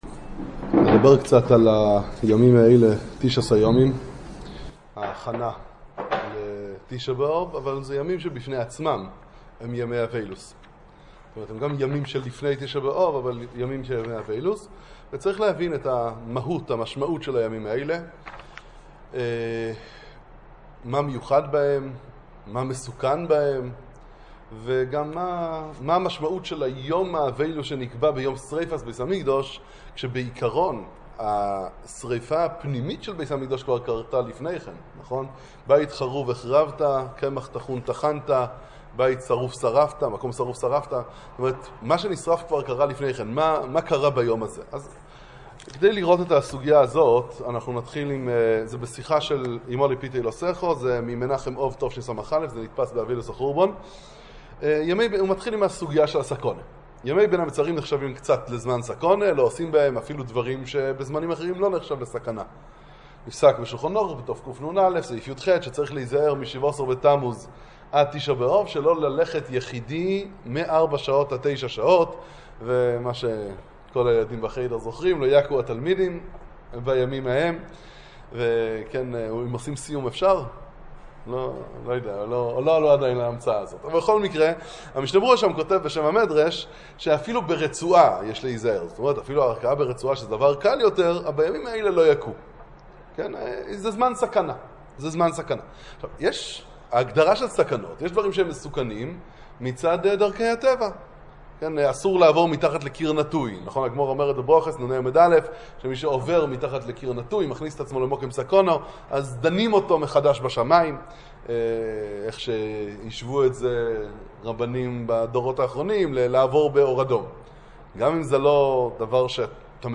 שיעור חיזוק לימים של סוף הזמן